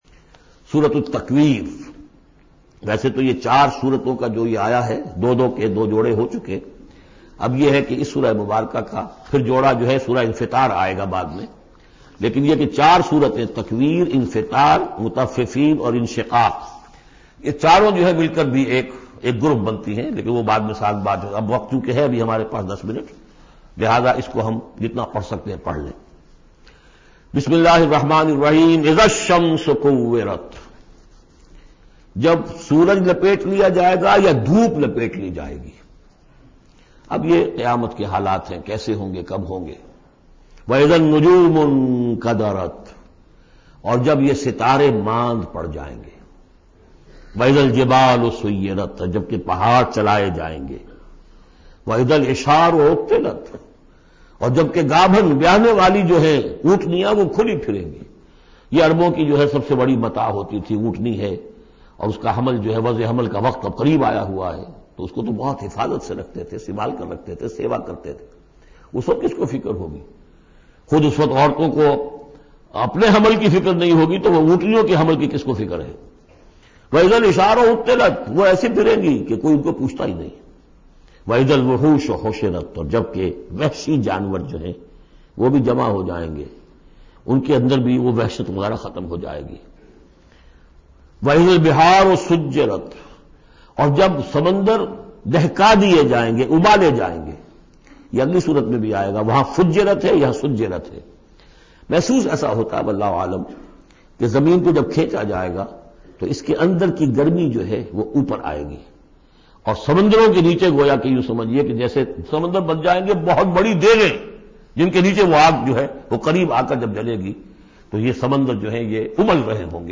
Surah Takwir Audio Tafseer by Dr Israr Ahmed
Surah Takwir is 81 chapter of Holy Quran. Listen online mp3 tafseer of Surah Takwir in the voice of Dr Israr Ahmed.